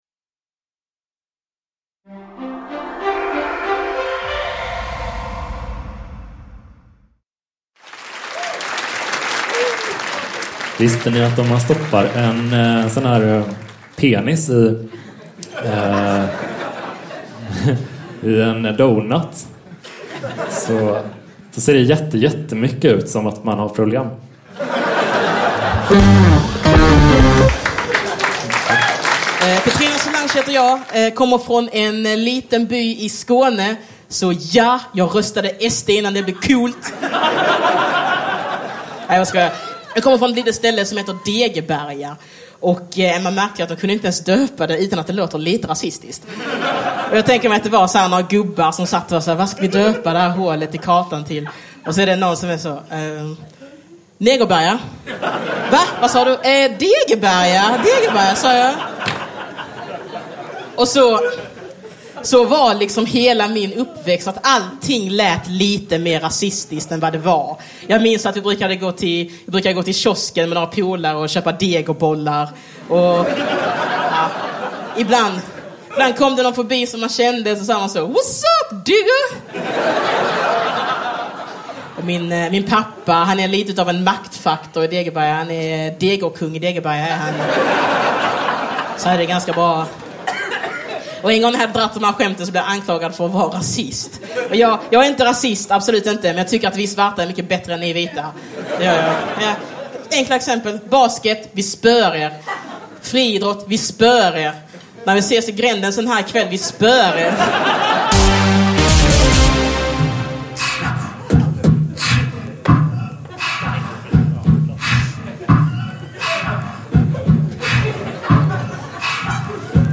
SVT-programmet "Släng dig i brunnen" sände humorshower av Sveriges främsta ståuppkomiker i över tio år. Namnet kom från inspelningslokalen: restaurangen Norra Brunn i Stockholm.
I denna ljudboksversion får vi ta del av skrattfester av bland andra Johan Glans, David Batra, Jonatan Unge, Måns Möller, Petrina Solange och Messiah Hallberg.
Medverkande komiker: